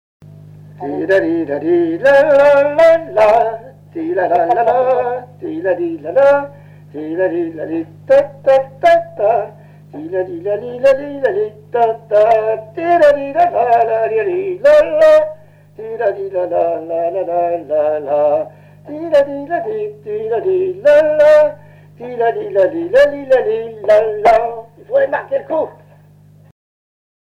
Mémoires et Patrimoines vivants - RaddO est une base de données d'archives iconographiques et sonores.
danse : mazurka
Pièce musicale inédite